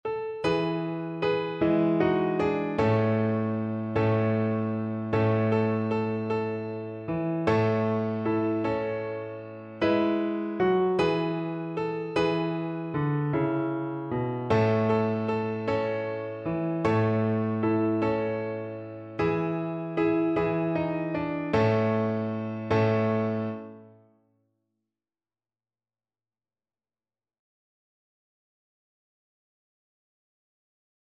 Violin
6/8 (View more 6/8 Music)
A major (Sounding Pitch) (View more A major Music for Violin )
Joyfully .=c.80
Traditional (View more Traditional Violin Music)